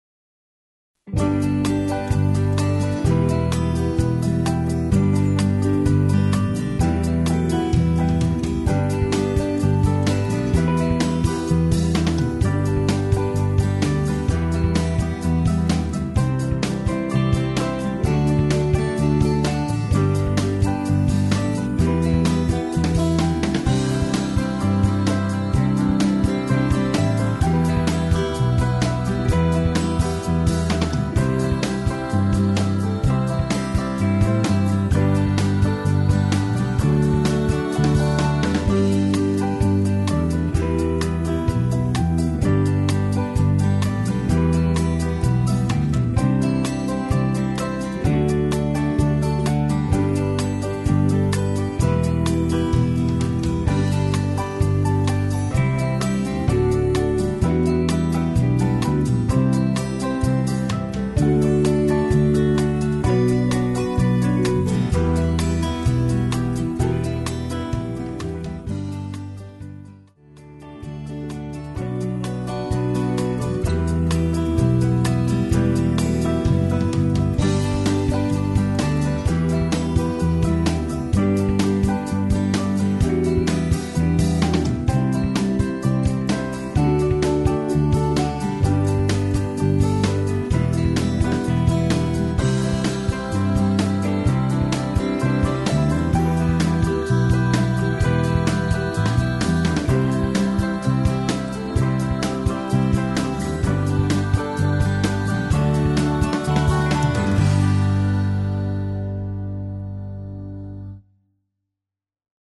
Instrumental TRacks
No Melody